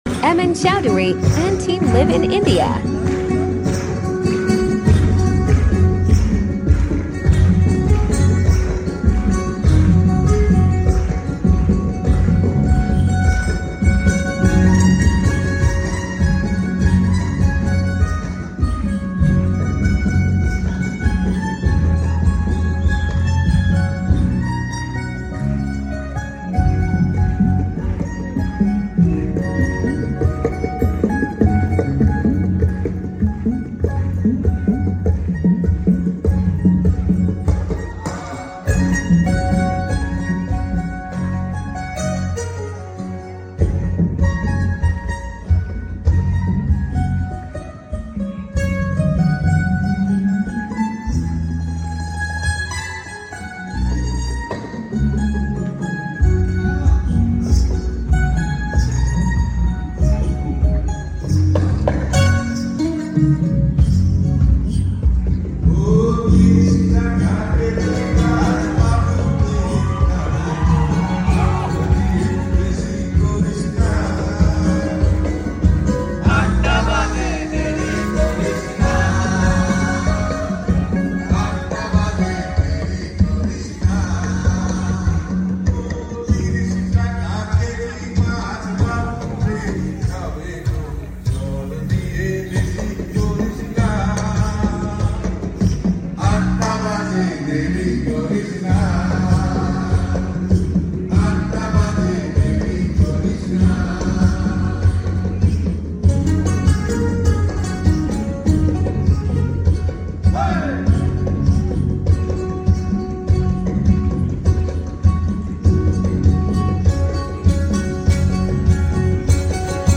গানটি ভারতের আগরতলায় Anim8tor Annul Awards-এ বাজিয়েছিলাম